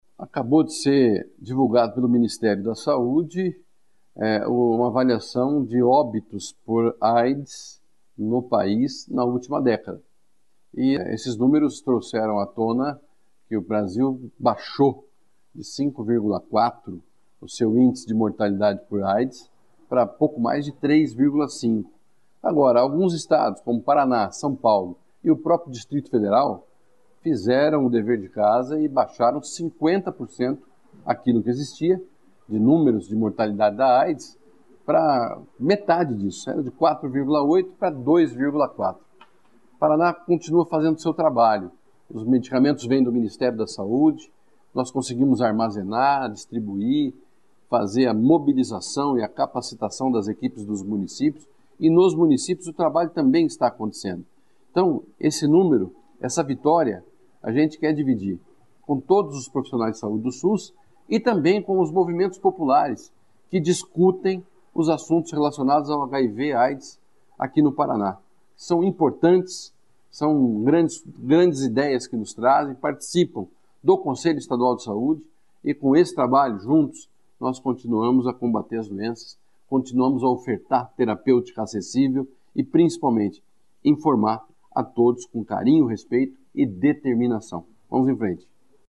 Sonora do secretário da Saúde, Beto Preto, sobre o Paraná ter uma das melhores coberturas na rede de atenção à Aids do Brasil